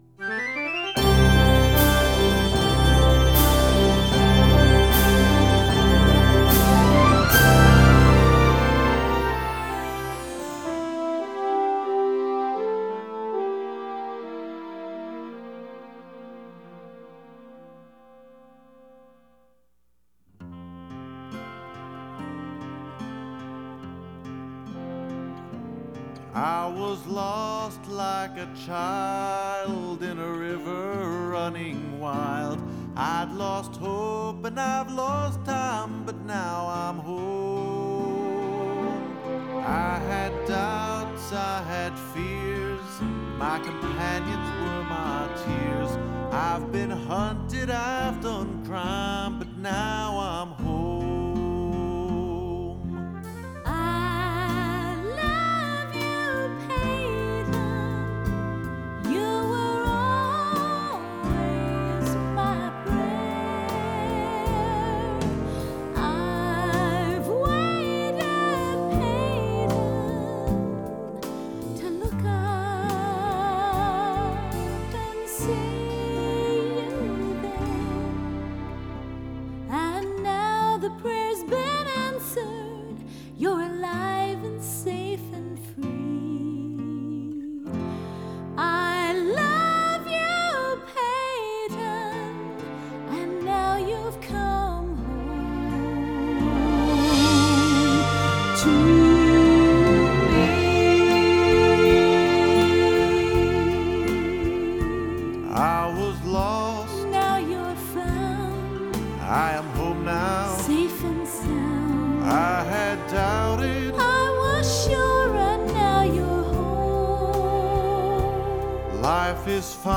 Guitars
Bass
Drums
Fiddle
Trombone